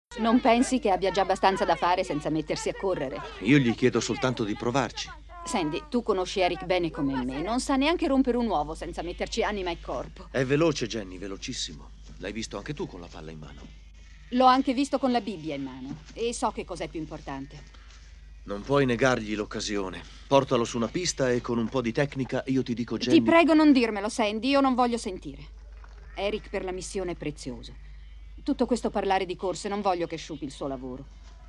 nei telefilm "X-Files", in cui doppia Sheila Larken, e "Law & Order: Unità speciale", in cui doppia Rita Moreno.